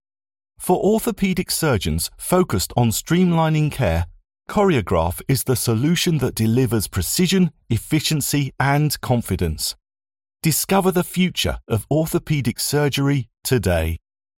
British English VO from London but based in glorious Yorkshire
Medical Short - Web Video - Adult Male